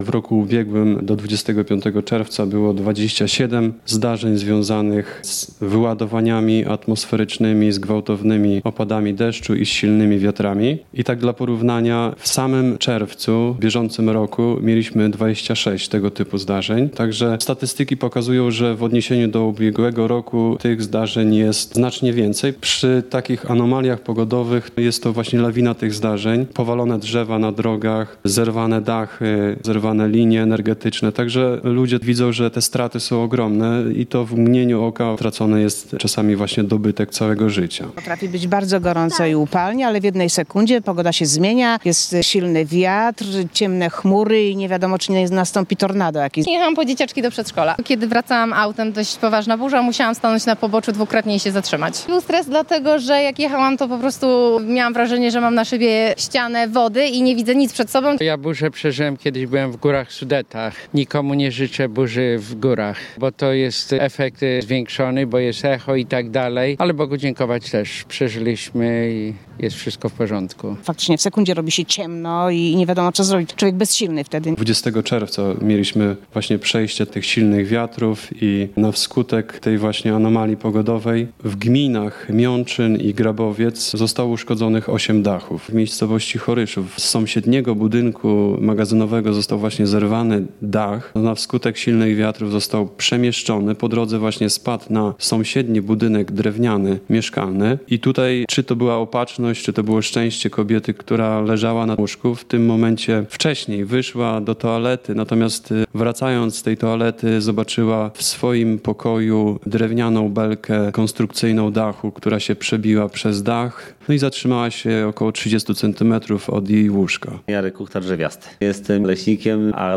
– W sekundę robi się ciemno i nie wiadomo co zrobić. Człowiek jest wtedy bezsilny – mówi jedna z mieszkanek Zamościa.